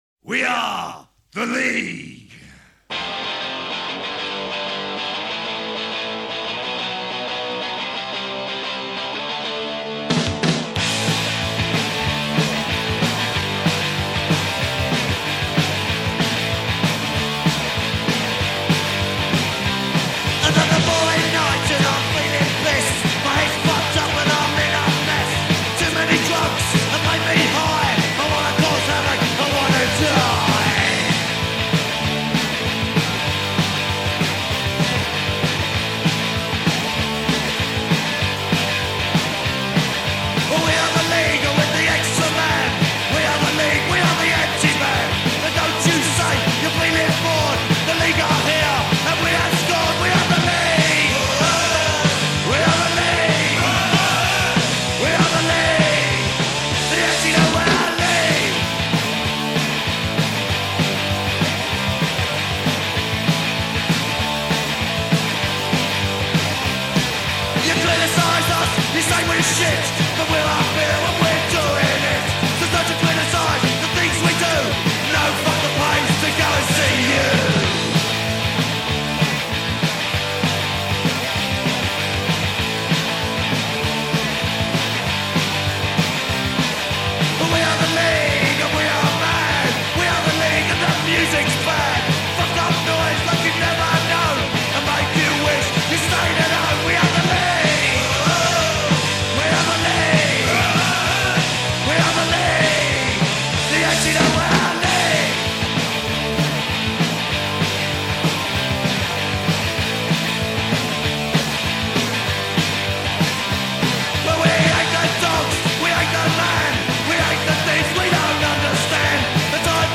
Punk Rock, Oi!